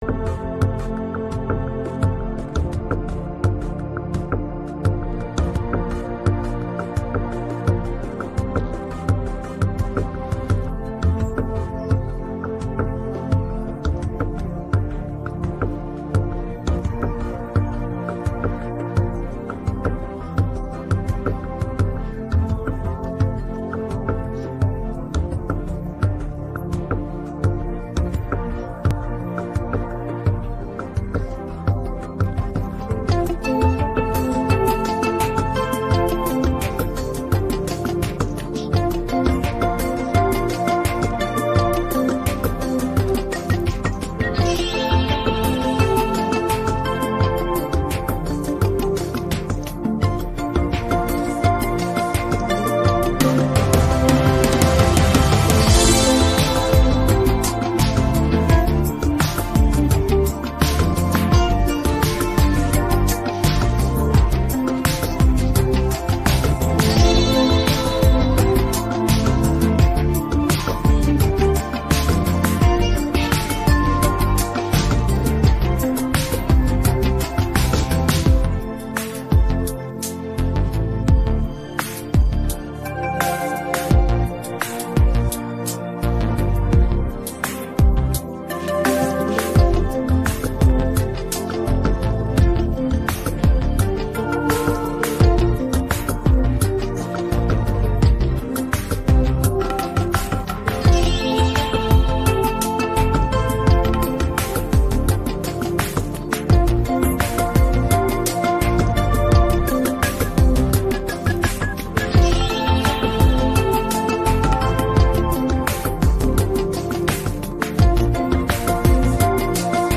pop rock караоке 16